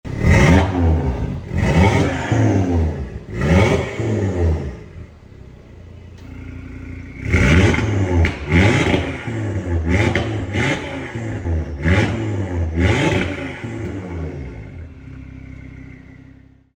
Listen to the DAZA symphony
• Wagner Decat Downpipe (£800)
• 2.5TFSI 5-Cylinder Engine: Aluminum Block Model